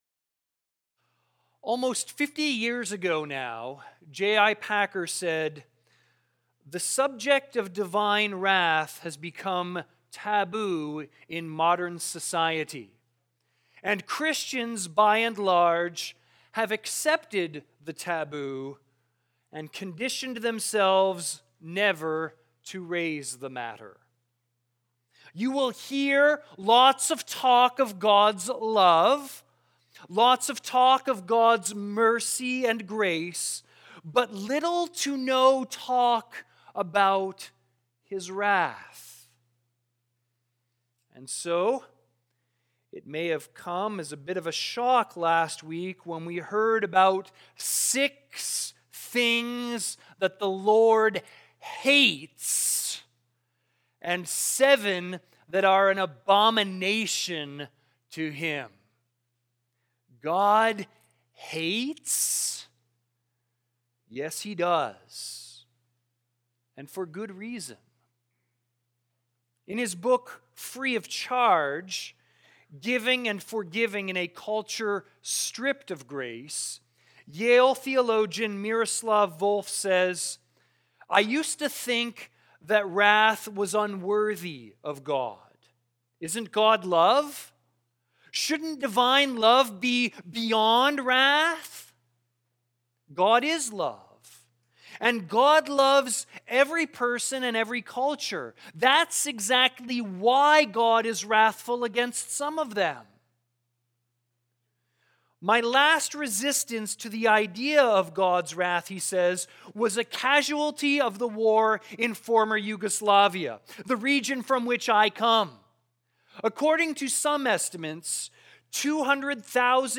Sermons | Campbell Baptist Church